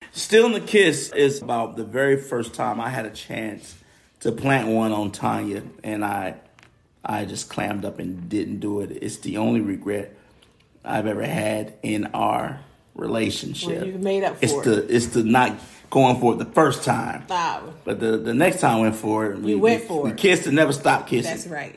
Michael Trotter, Jr. and his wife Tanya Trotter, aka The War And Treaty, talk about the inspiration behind their new song, "Stealing A Kiss."